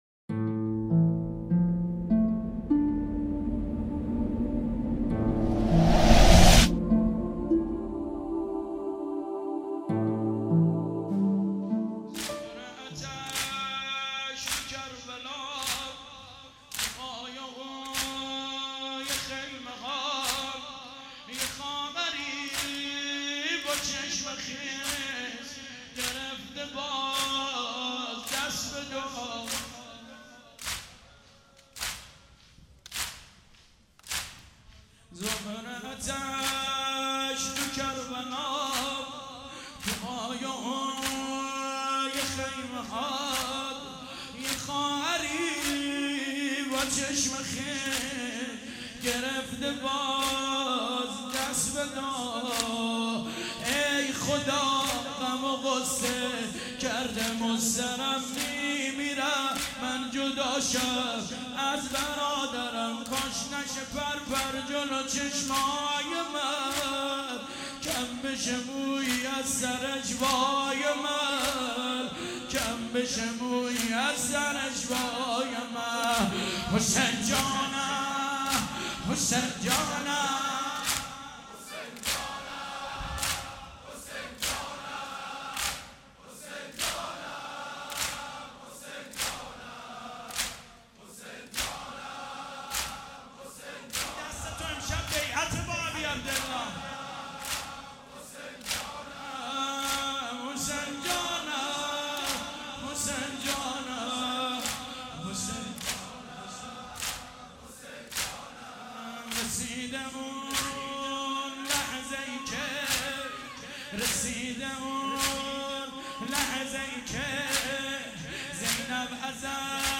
زمینه | شب عاشورا محرم 1397 | هیأت غریب مدینه